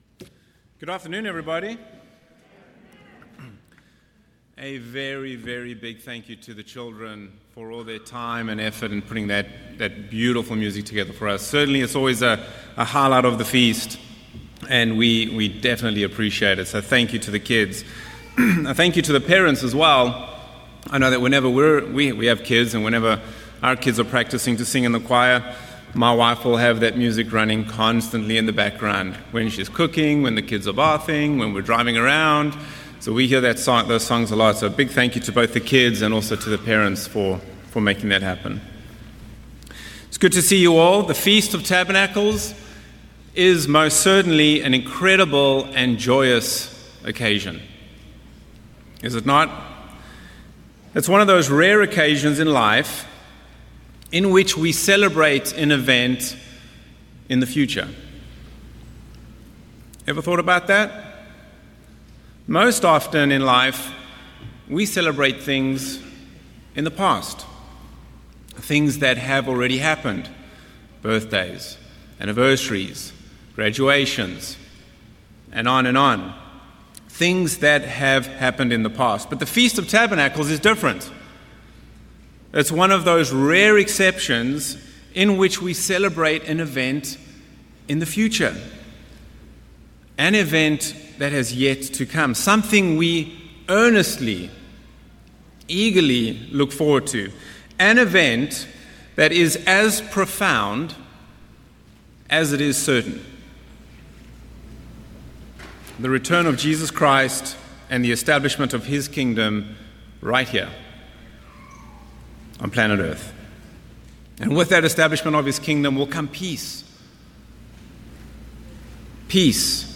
In this message, we explore Matthew 18’s framework for reconciliation, emphasizing that world peace begins with each of us. By applying Christ’s teachings now within God's spiritual family, we set an example for all mankind to model in the future.